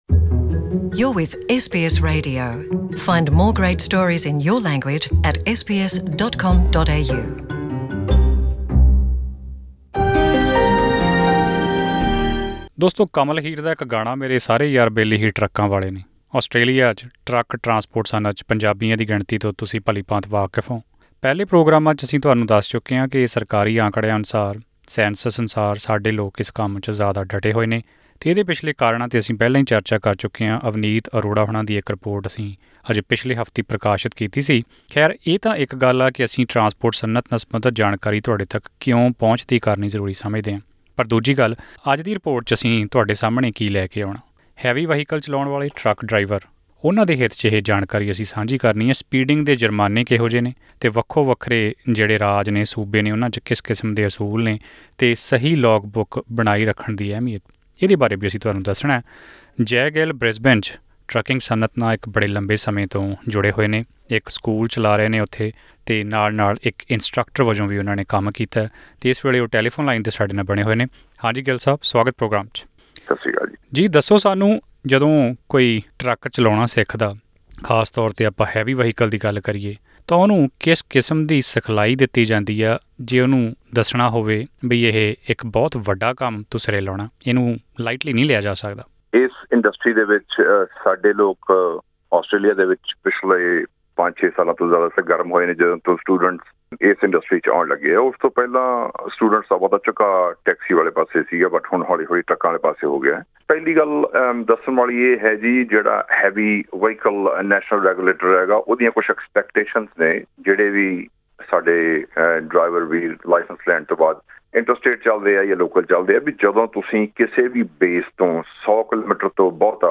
ਇਸ ਆਡੀਓ ਰਿਪੋਰਟ ਵਿੱਚ ਜਾਣੋ ਕਿ ਇਹਨਾਂ ਨਿਯਮਾਂ ਦੀ ਪਾਲਣਾ ਕਰਨਾ ਕਿਓਂ ਜਰੂਰੀ ਹੈ?